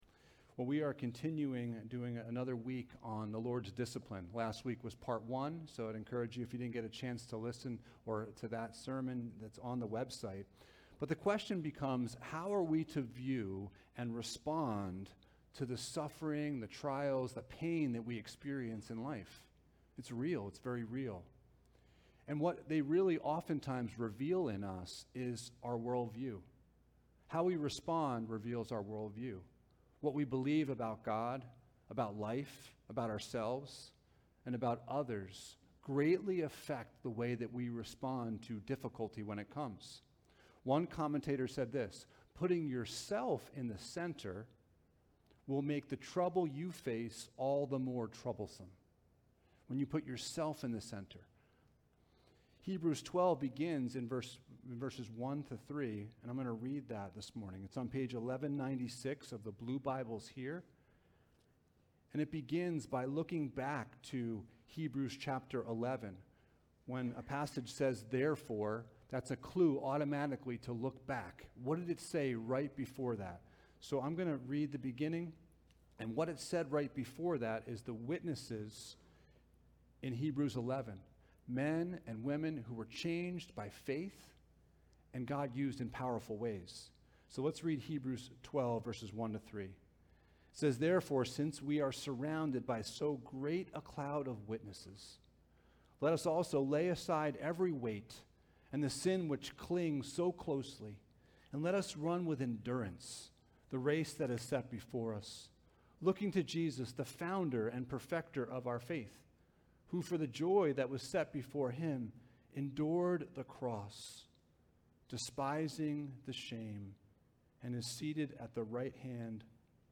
Jesus is Better Passage: Hebrews 12:1-11 Service Type: Sunday Morning « The Lord’s Discipline